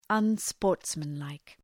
Shkrimi fonetik{ʌn’spɔ:rtsmən,laık}
unsportsmanlike.mp3